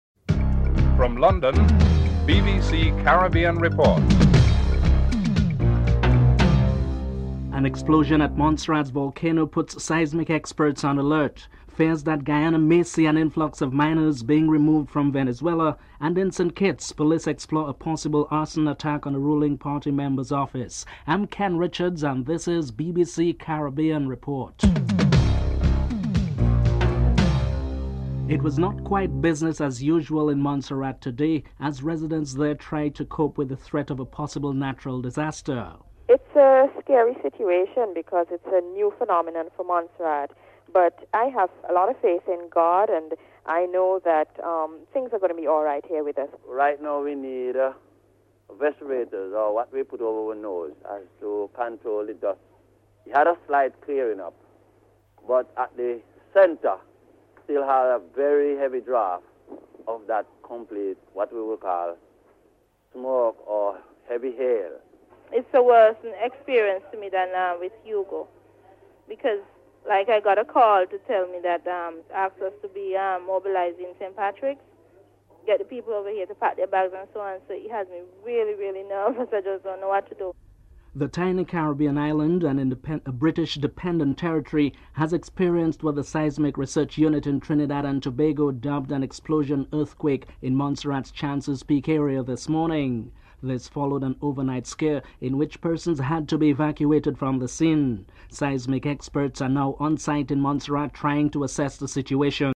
1. Headlines
9. Recap of top stories (14:46-15:02)